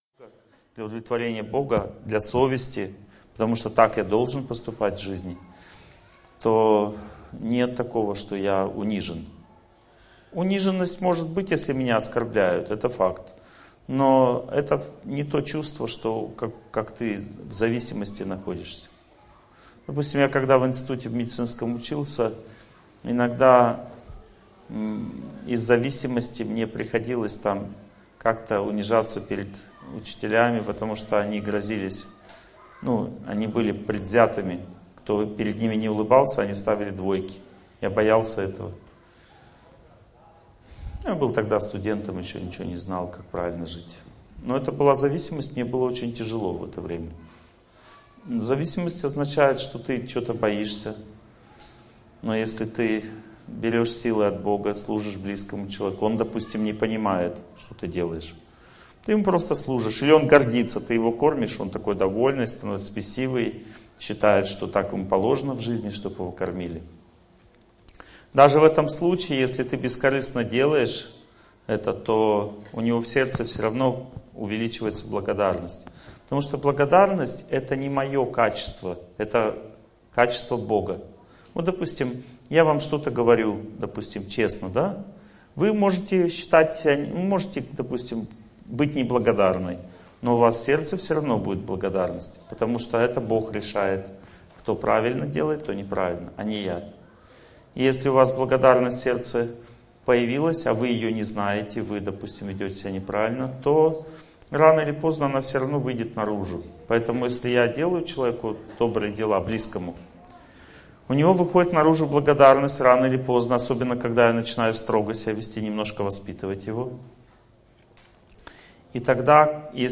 Аудиокнига Победа над стрессом и трудностями судьбы. Глава 3 | Библиотека аудиокниг